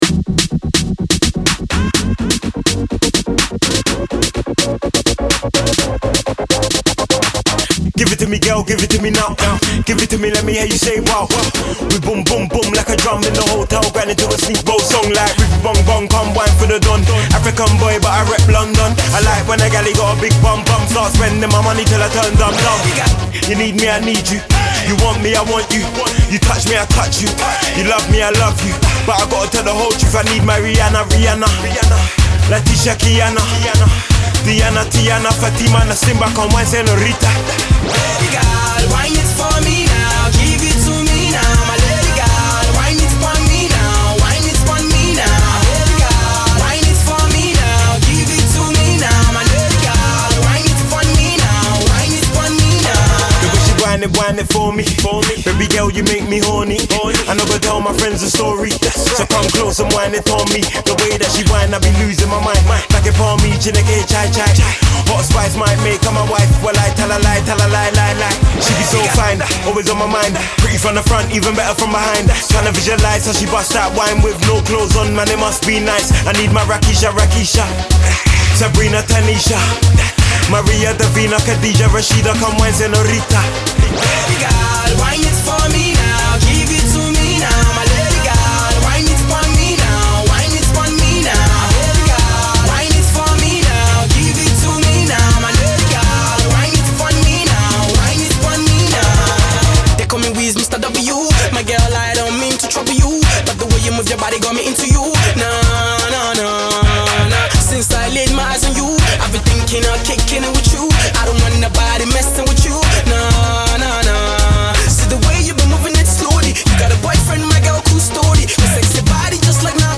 Alternative Pop
infectious vocals